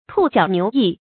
兔角牛翼 注音： ㄊㄨˋ ㄐㄧㄠˇ ㄋㄧㄨˊ ㄧˋ 讀音讀法： 意思解釋： 兔不生角，牛不長翼，故以「兔角牛翼」喻不合情理之事。